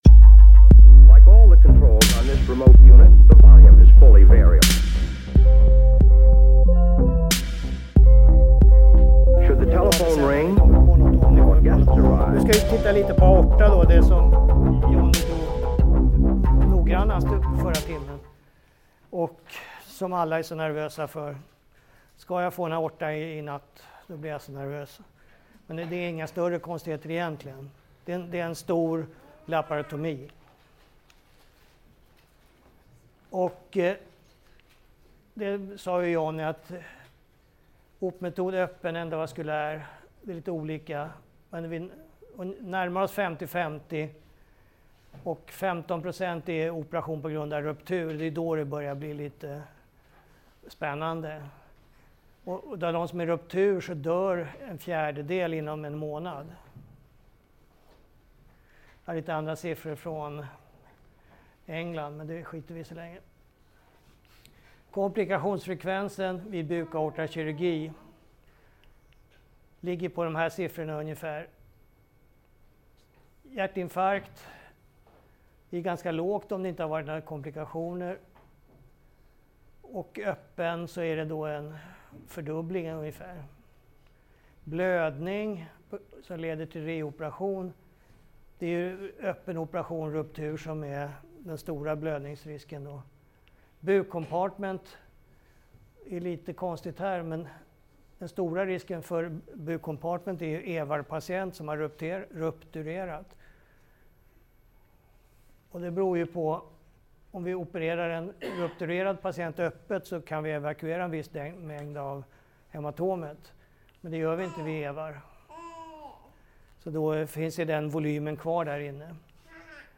Inspelningen gjordes under ST-fredagen om anestesi vid kärlkirurgi på SöS, 5 februari 2019.